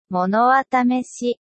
(Google Translateのエンジンを使用した、Sound of Textによるテキスト読み上げ)